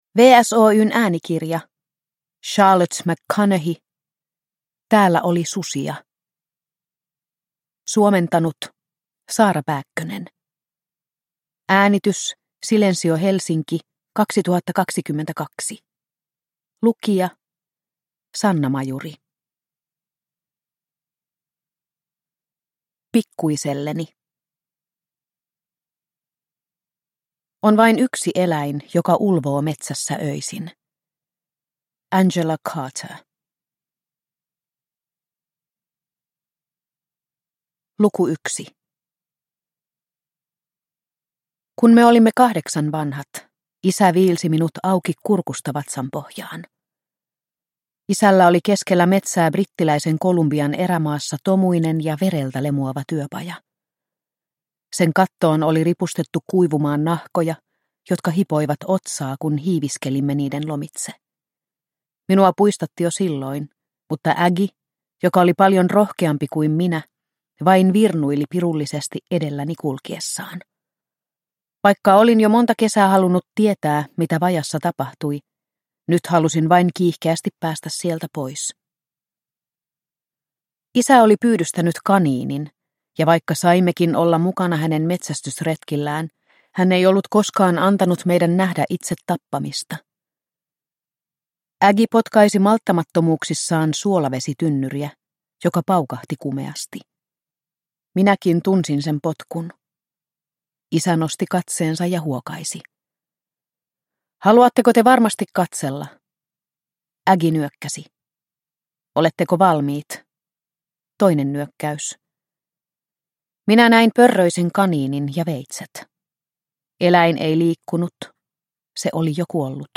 Täällä oli susia – Ljudbok – Laddas ner